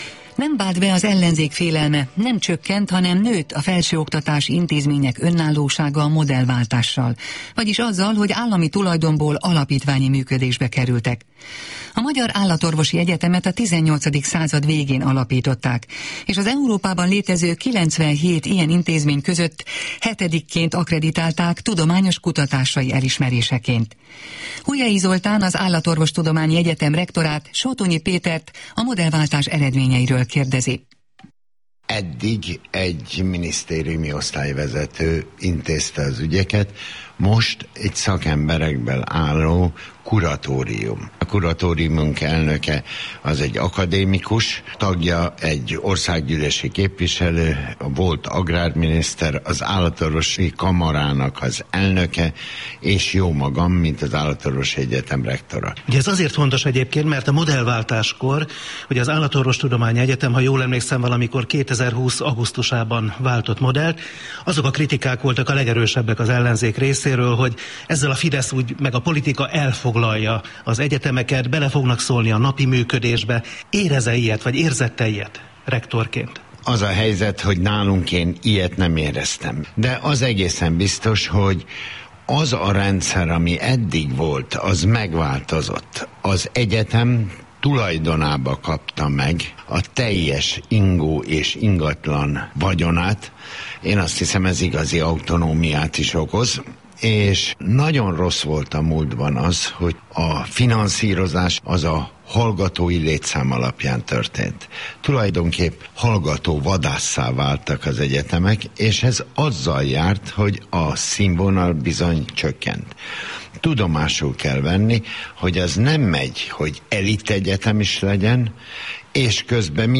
interjúja a Kossuth Rádióban